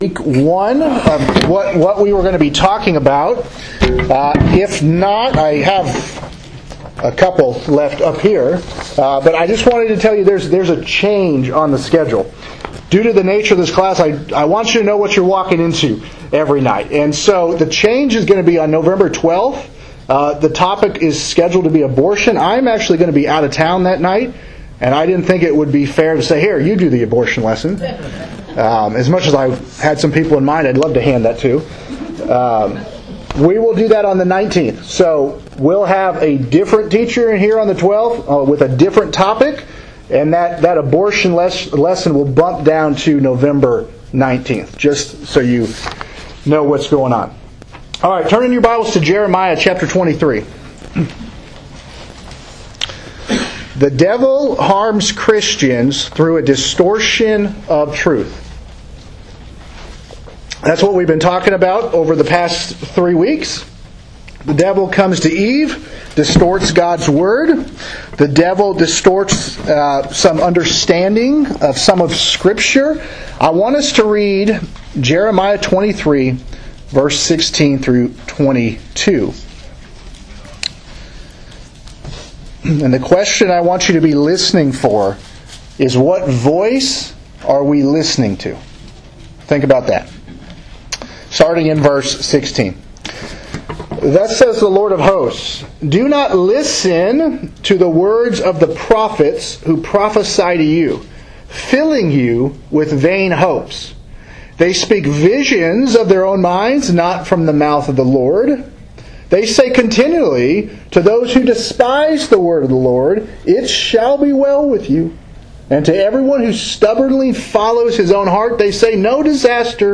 BIBLE CLASS - Sexual Immorality